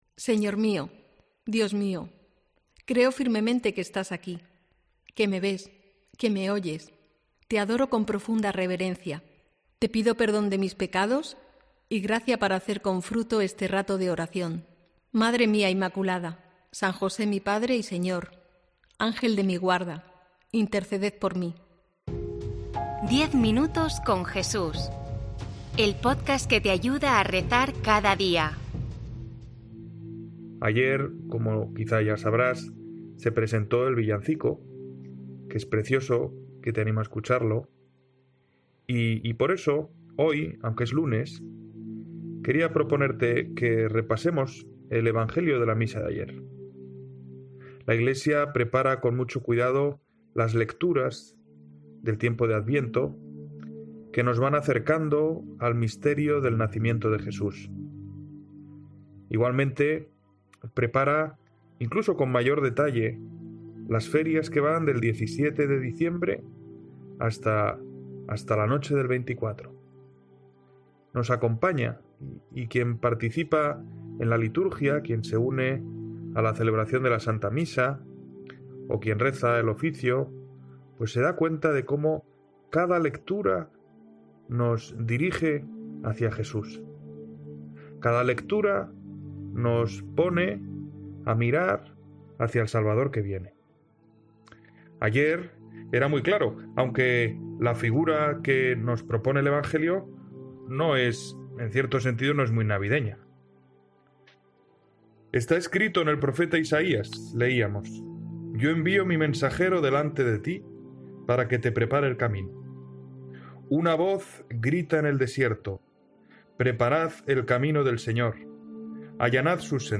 Desde este lunes los diferentes canales digitales de COPE, incluido ECCLESIA, difundirán esta meditación diaria grabada por sacerdotes de todo el mundo...